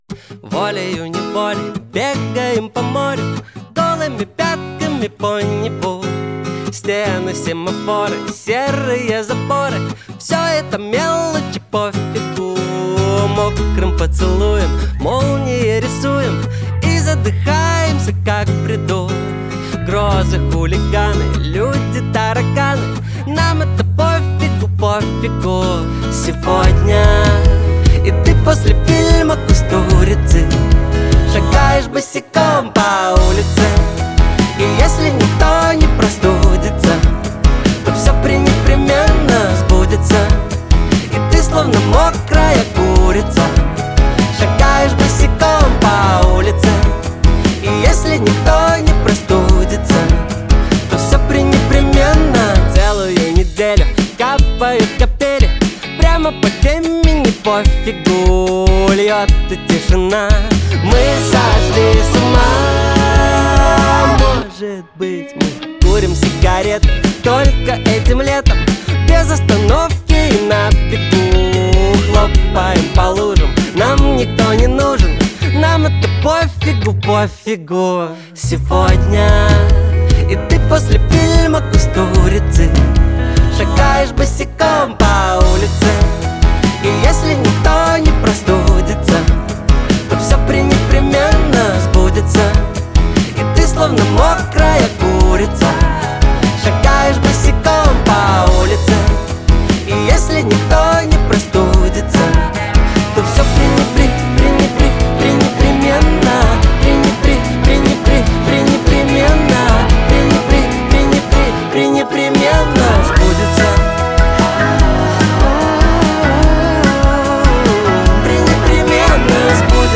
Клубные хиты